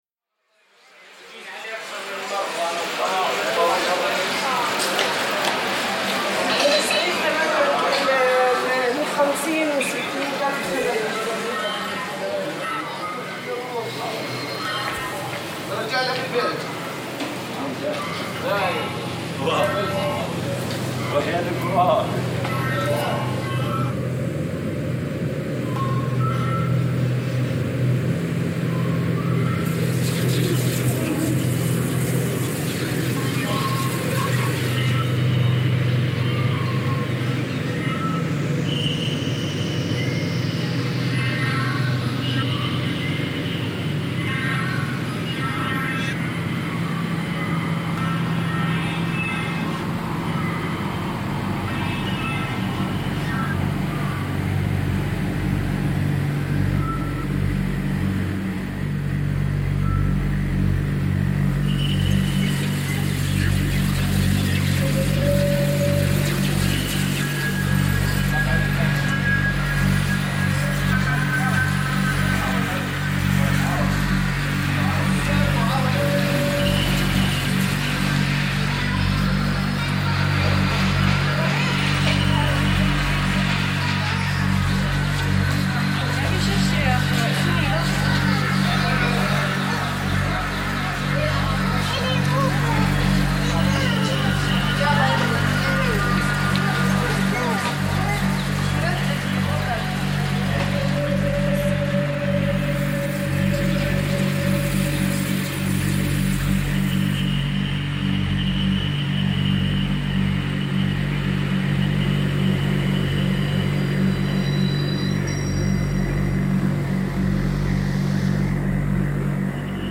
Aside from the drone and occasional percussions, most of the sounds are heavily treated vocals. Next, I liked the organic side of the market's ambiance, therefore some live percussions was added.
The drone and percussions we're added to complete the picture.